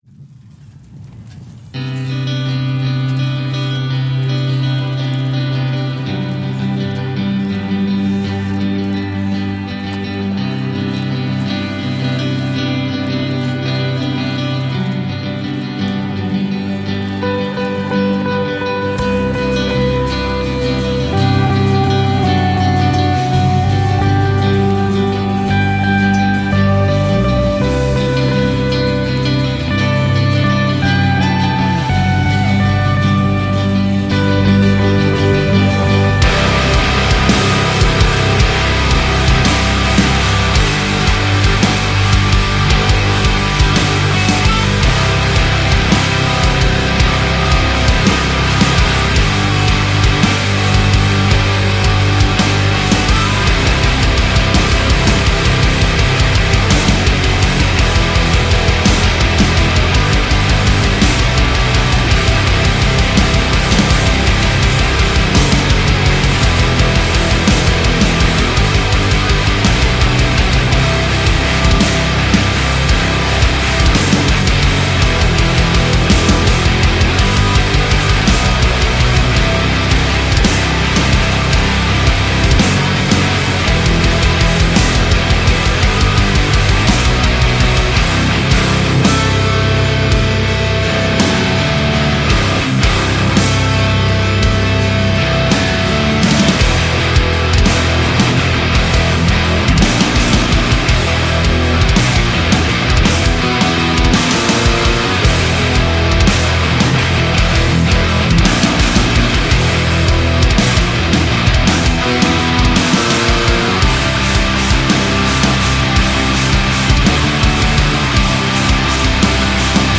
4 piece rock
a stunning blend of instrumental post-rock and metal.